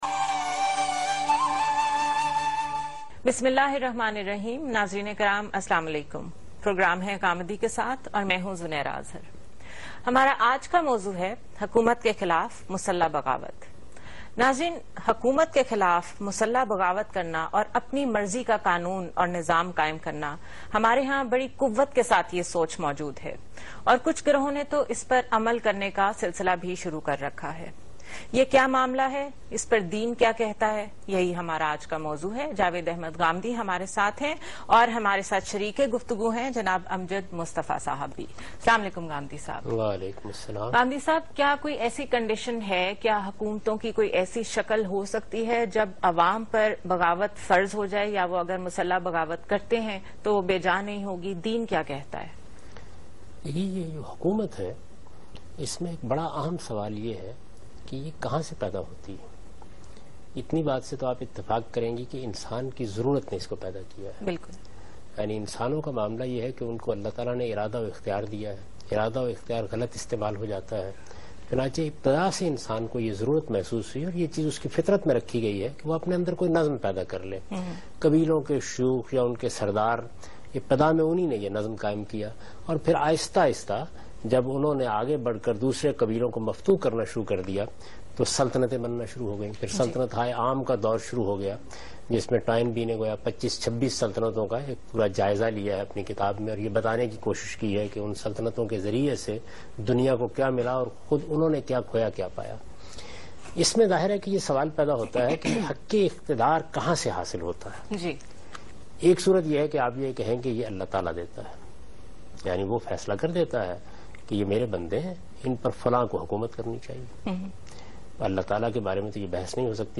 A Talk show
aired on Samaa TV on 27th Sep 2013.